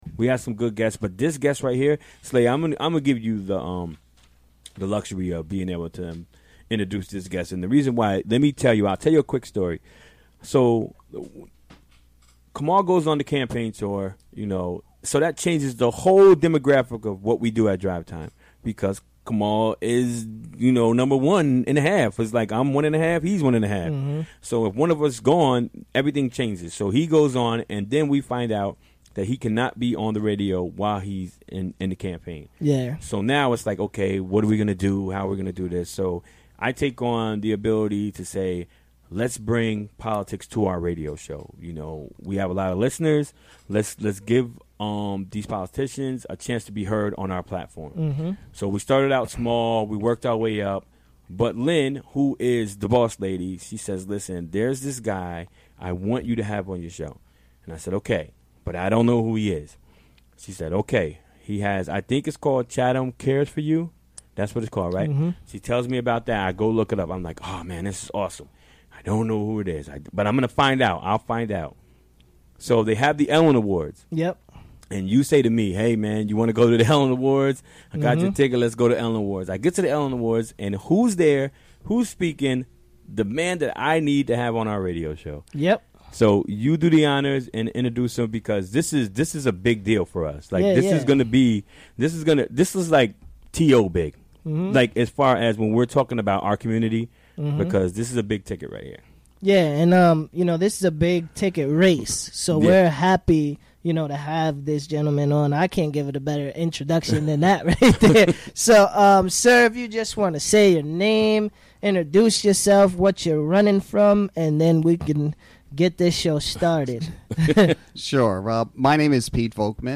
Recorded during the WGXC Afternoon Show Wednesday, October 25, 2017.